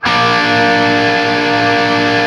TRIAD A# L-R.wav